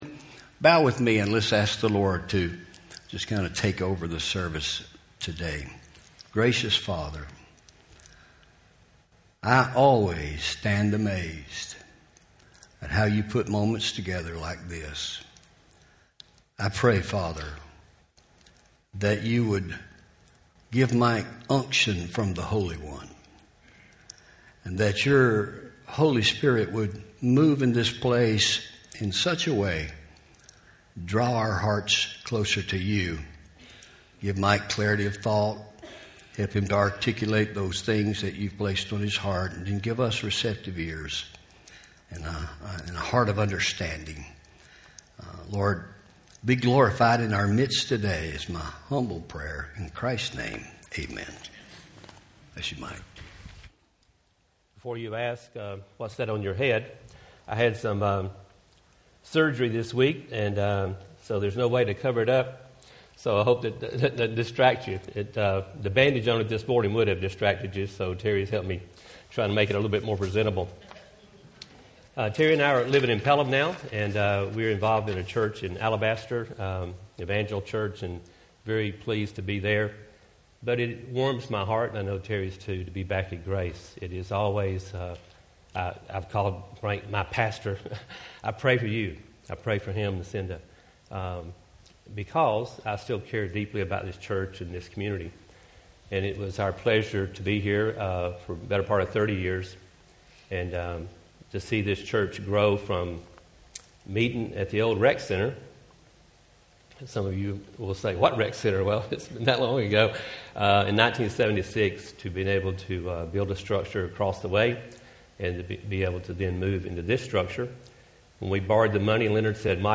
Home › Sermons › From The Penthouse To Persecution